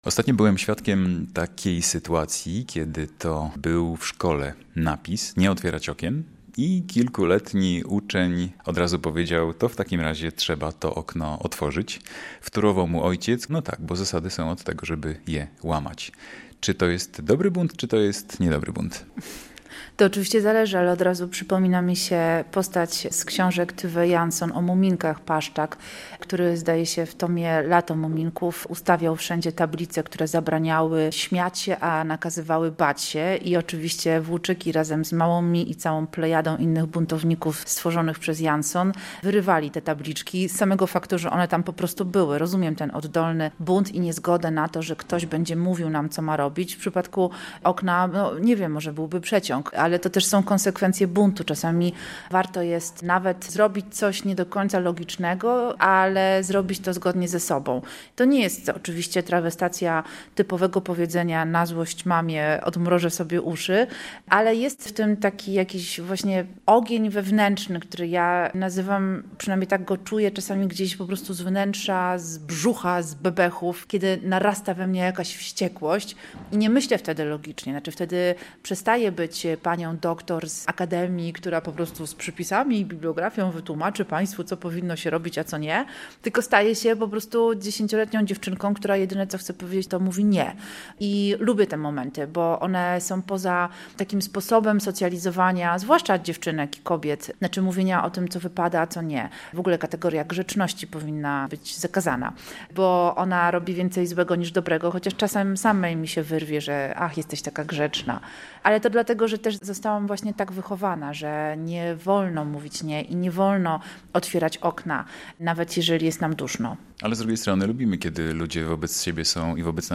9. Targi Książki i festiwal "Na pograniczu kultur" w Białymstoku
Z Sylwią Chutnik rozmawia